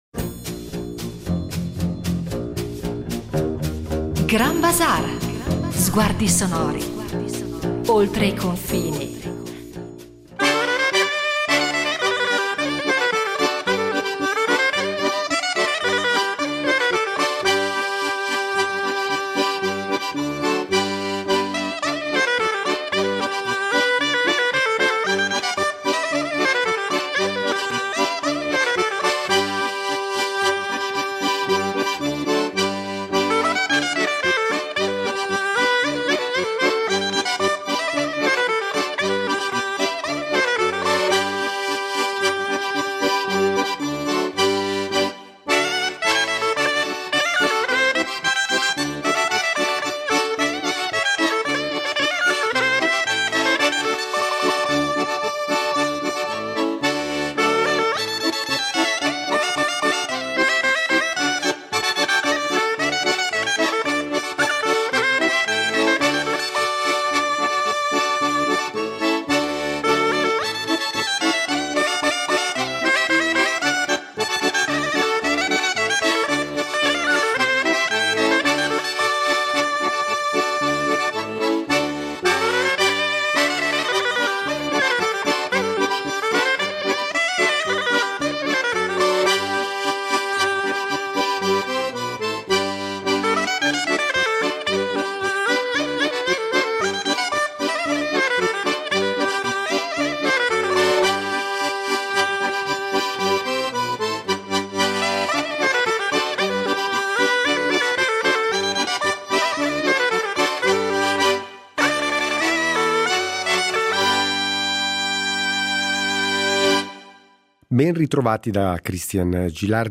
Grand Bazaar tra le note del piffero (2./2)